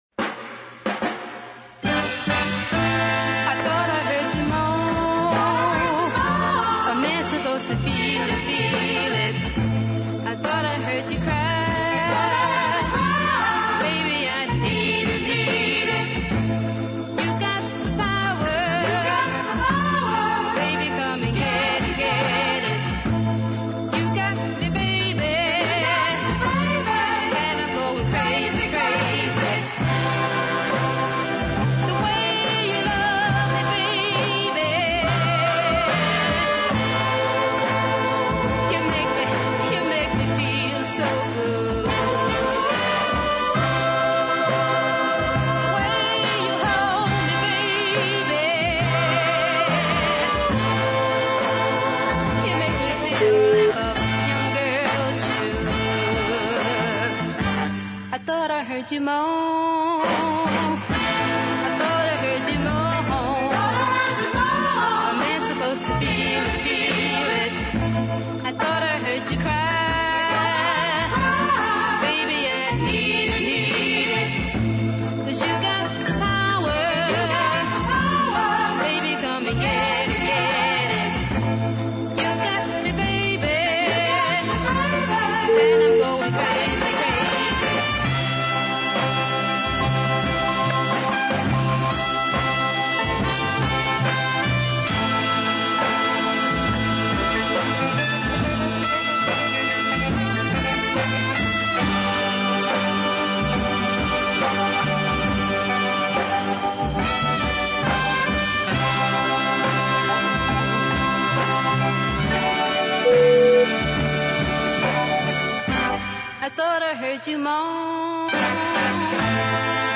a similarly chorded piece of deep soul